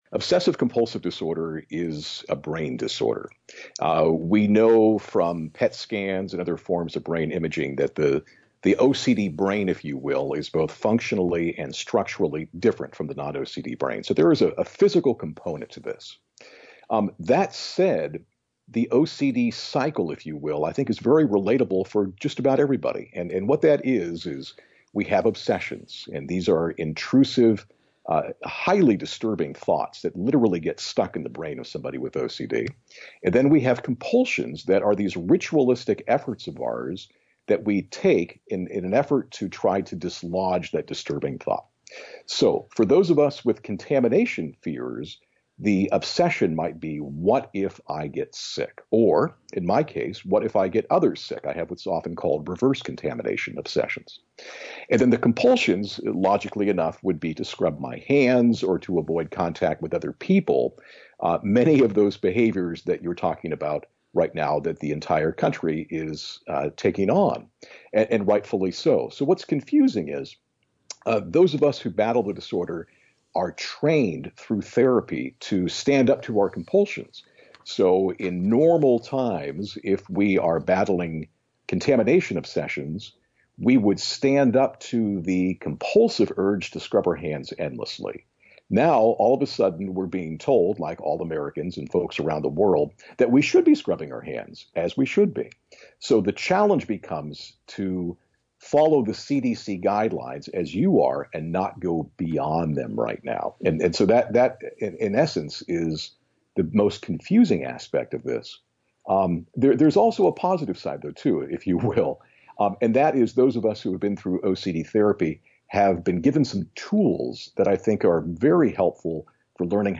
In-Depth Interview: Covid-19 Creates Heightened Stress for People With OCD